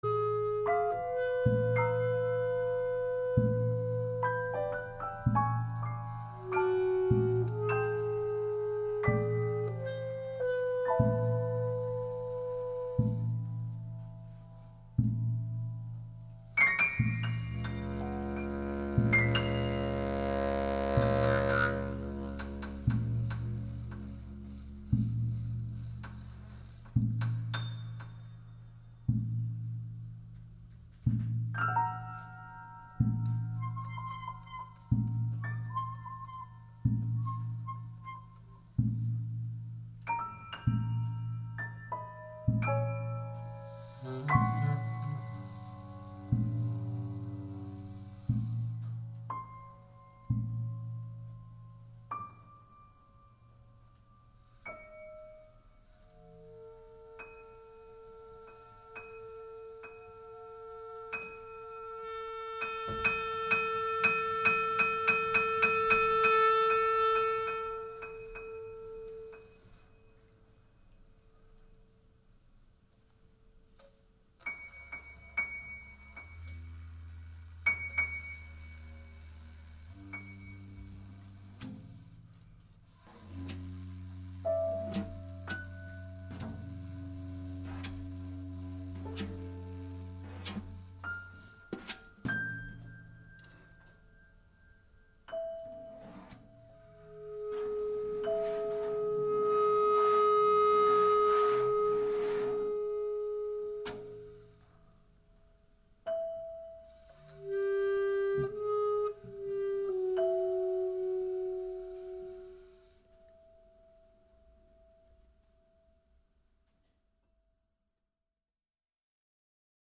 American pianist
saxophonist & clarinetist